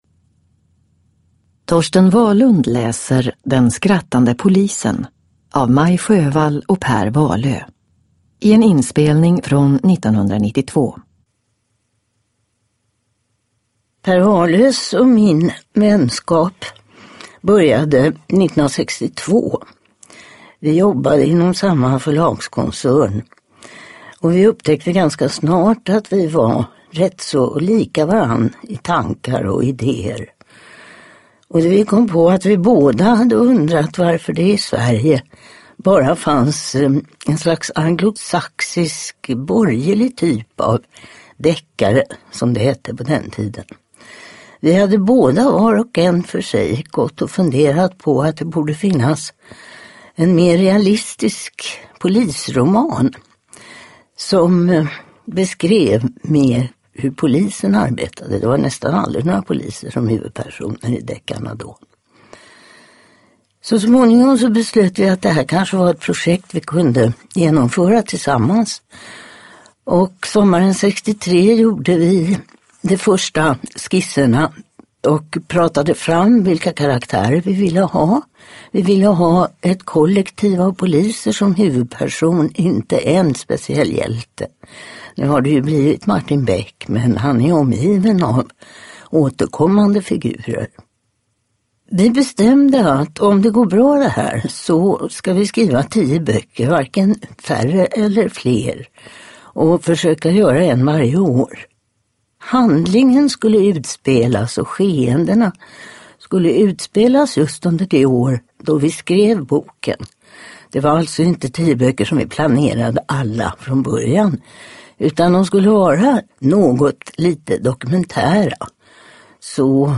Den skrattande polisen – Ljudbok – Laddas ner
Uppläsare: Torsten Wahlund